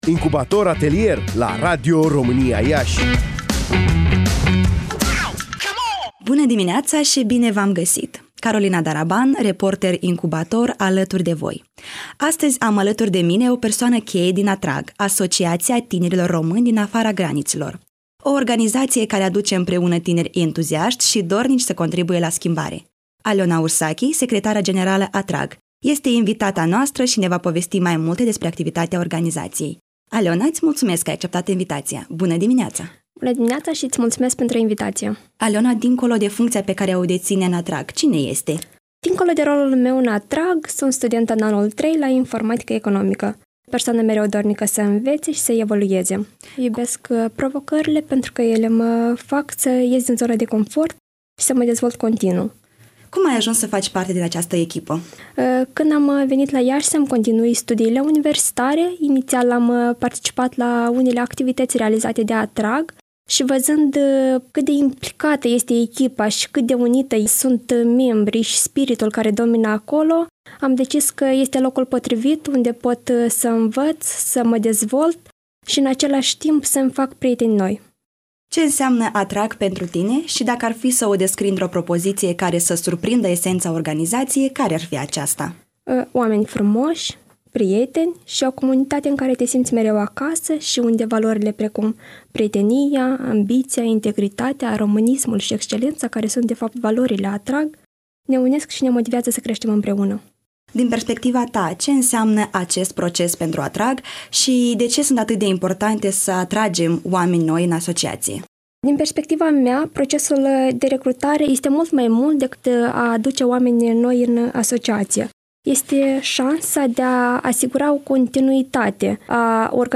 în direct